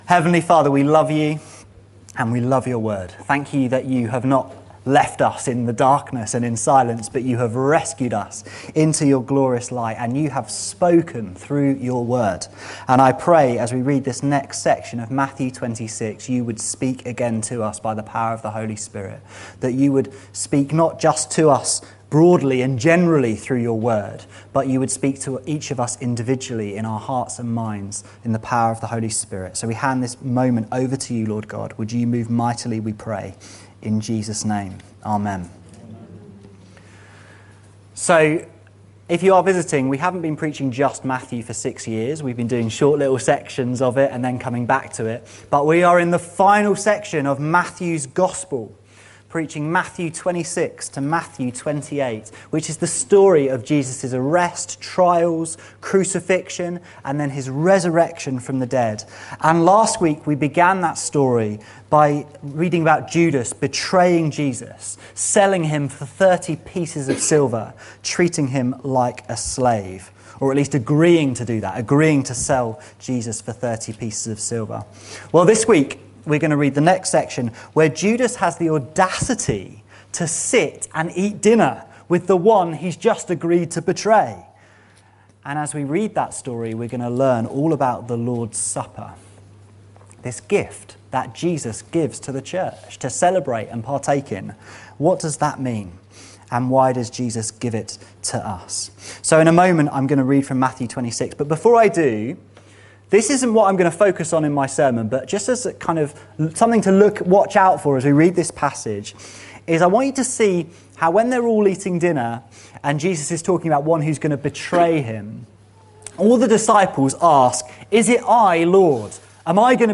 This sermon proclaims how precious a sacrament the Lord’s Supper is to all God’s children until Jesus returns.